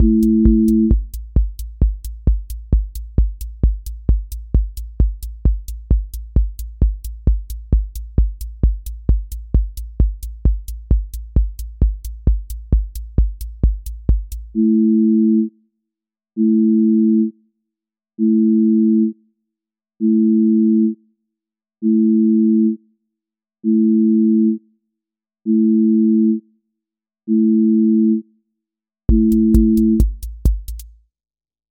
QA Listening Test trance Template: trance_euphoria
trance ascent with breakdown and drop
• voice_kick_808
• voice_hat_rimshot
• voice_sub_pulse
• fx_space_haze_light
• tone_brittle_edge